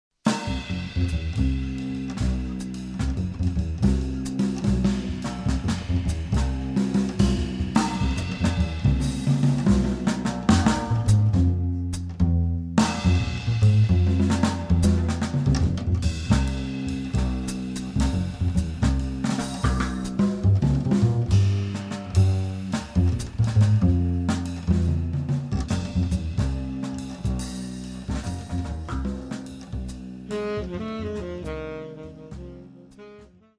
sax
guitar
piano
bass
drums